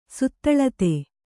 ♪ śithilate